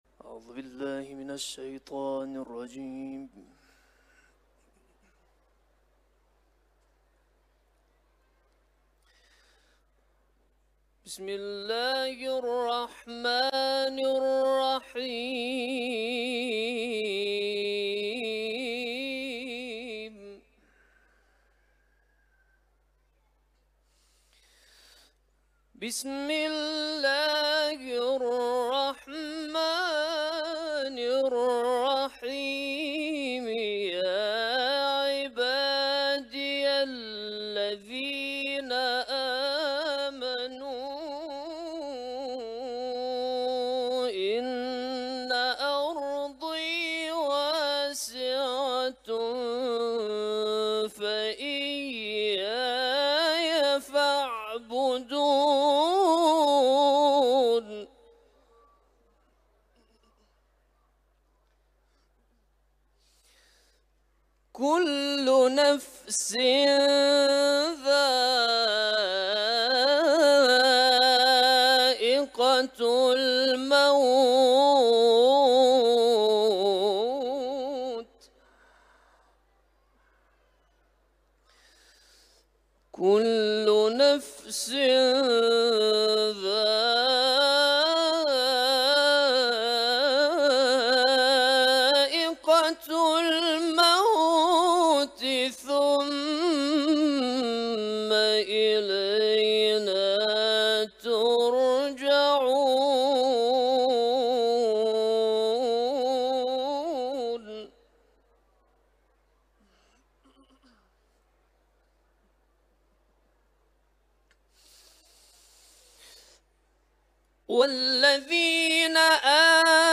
Kur'an-ı Kerim
Etiketler: İranlı kâri ، Kuran tilaveti ، Ankebut suresi